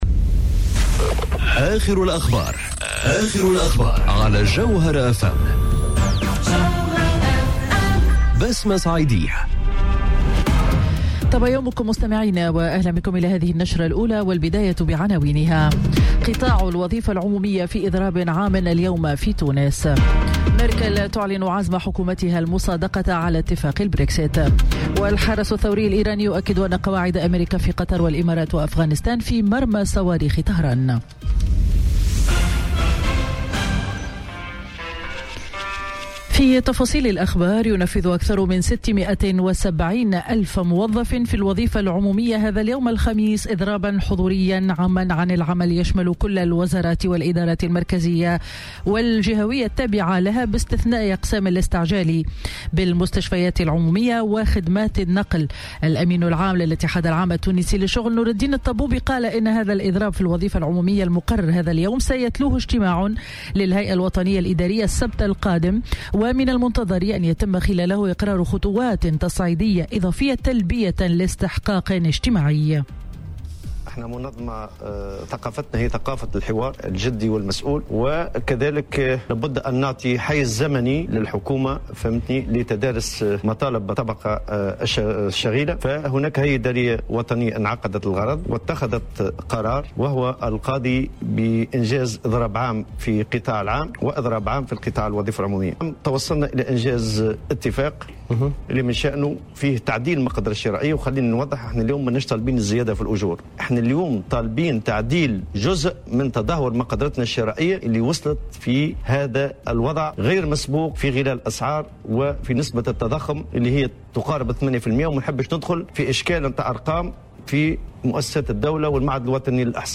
نشرة أخبار السابعة صباحا ليوم الخميس 22 نوفمبر 2018